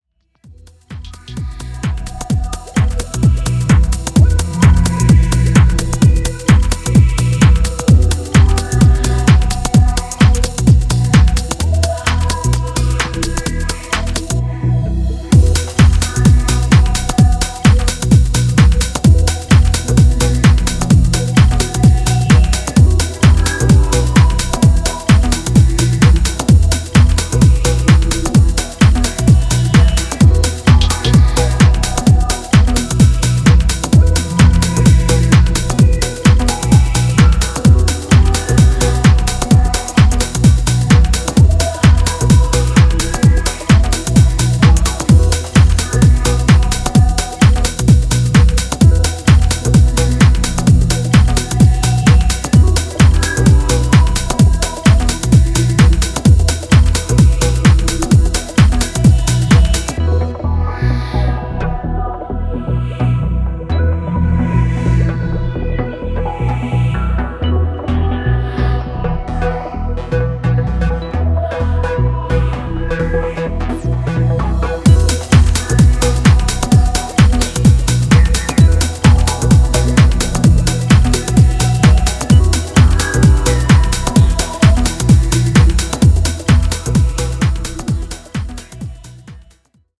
UKGを現代的かつミニマルに昇華した